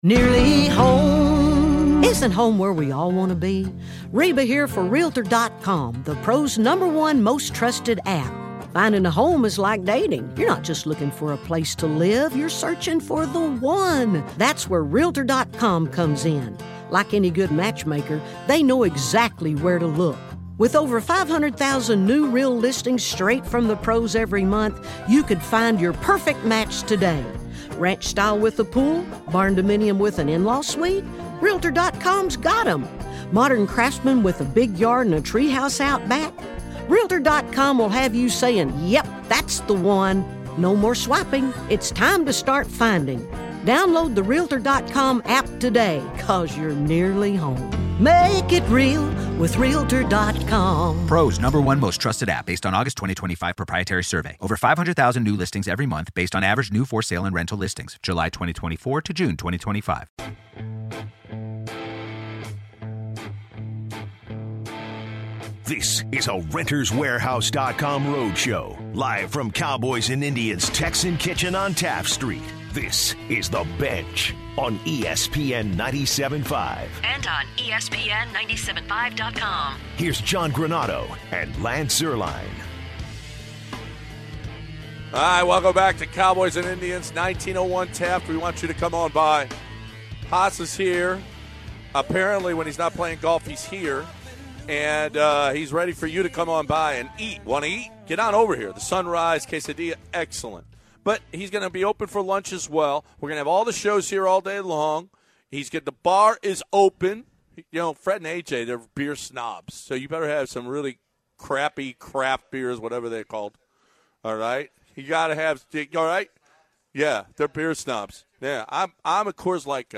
The guys break down a car chase that was happening live on air. The guys transition to tonight’s NCAA tournament matchups and who they think has the advantage to make it to the elite 8. To close out the second hour the guys play some Deshaun Watson sound and discuss his comments regarding Tyron Mathieu.